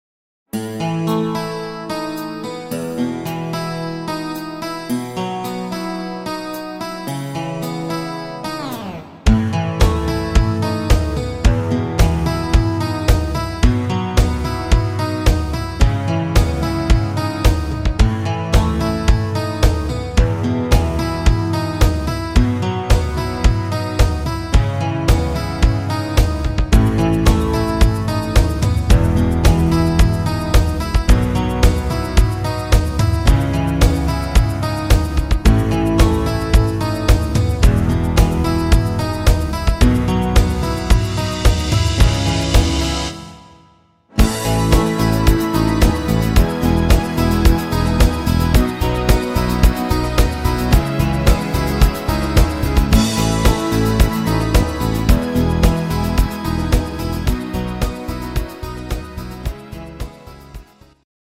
poppiger Rock-Schlager